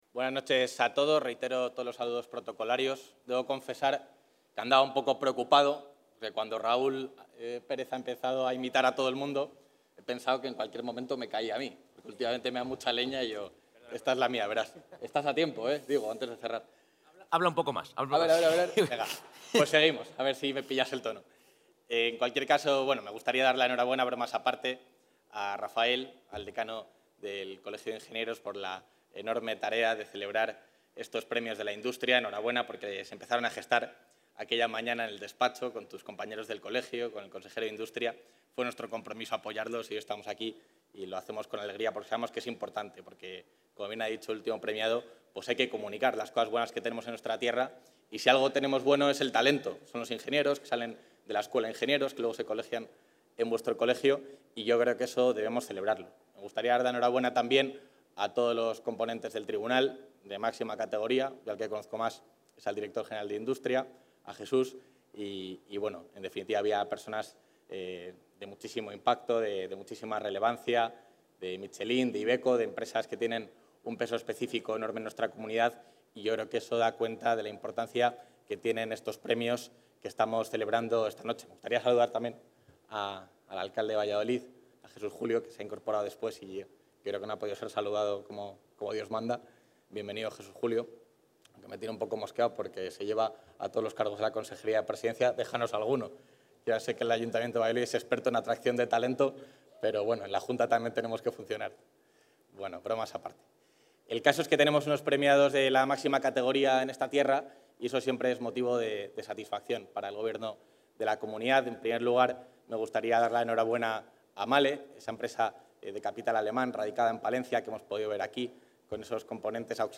Intervención del vicepresidente de la Junta.
García-Gallardo clausura la gala de los VI Premios de la Industria con una llamada al optimismo para los jóvenes.